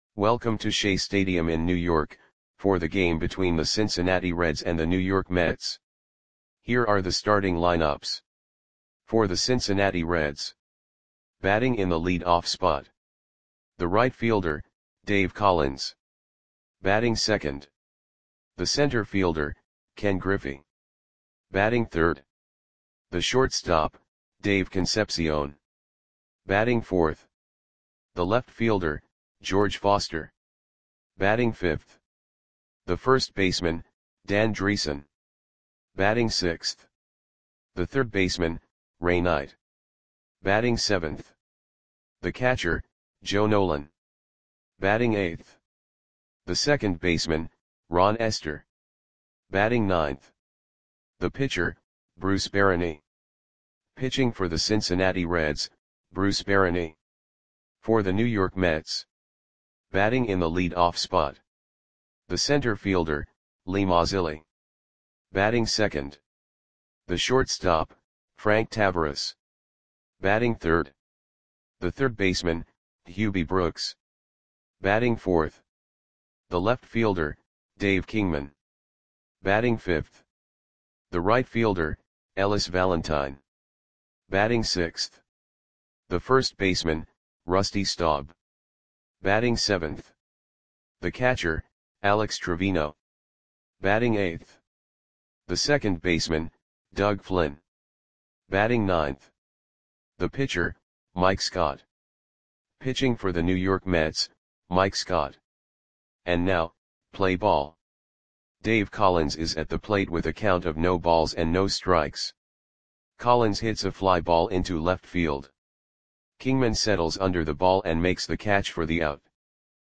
Lineups for the New York Mets versus Cincinnati Reds baseball game on August 29, 1981 at Shea Stadium (New York, NY).
Click the button below to listen to the audio play-by-play.